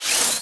Sfx_tool_hoverpad_arm_up_01.ogg